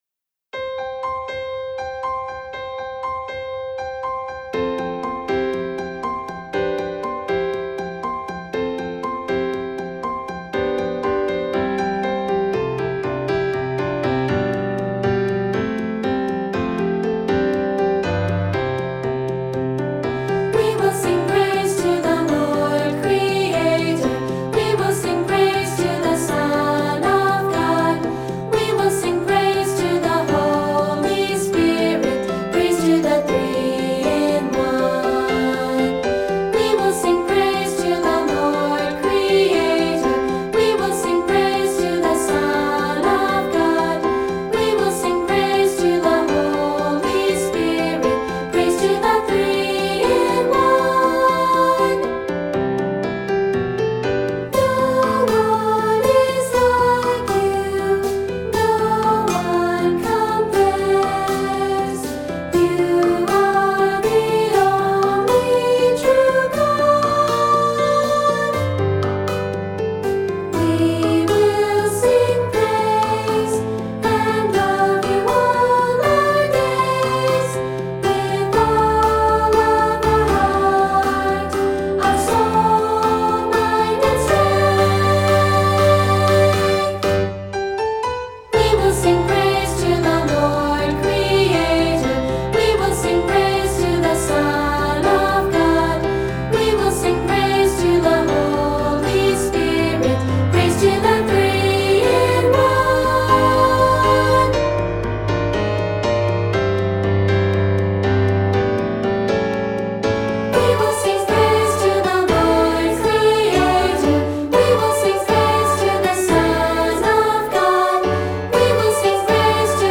Voicing: Unison/2-Part, opt. Percussion